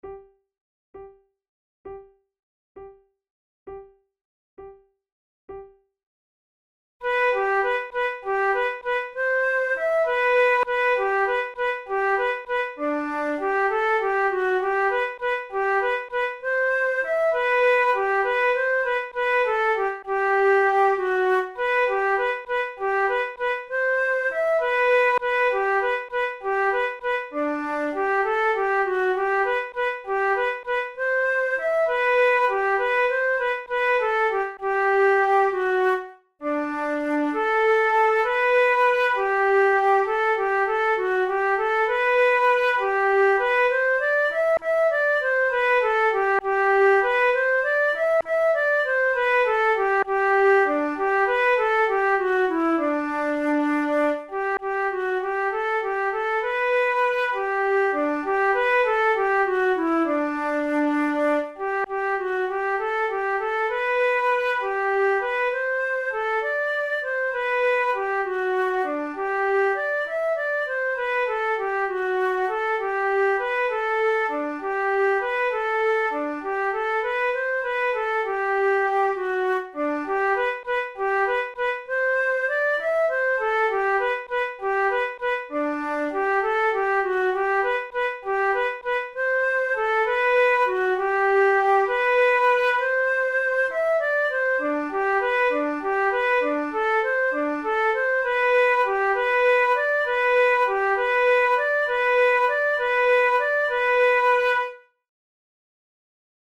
InstrumentationFlute duet
KeyG major
Time signature6/8
Tempo66 BPM
Classical, Written for Flute